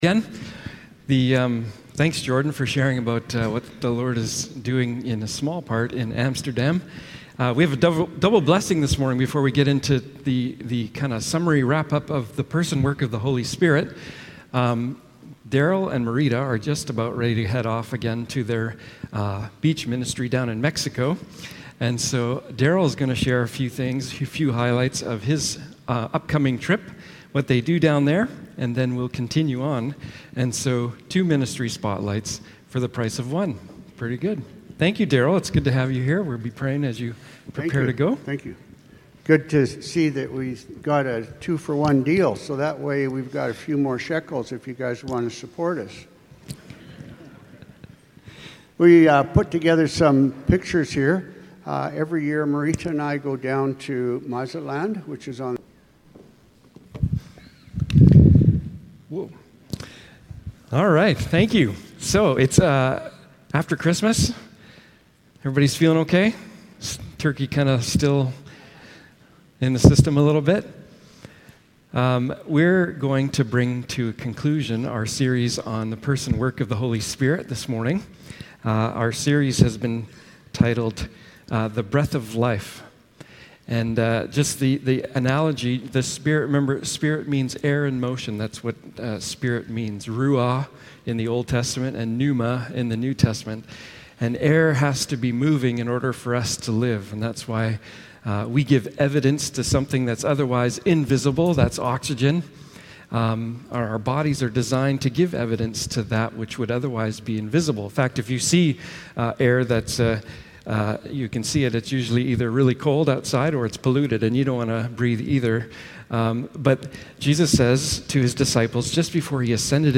Passage: Acts 1:8 Service Type: Morning Service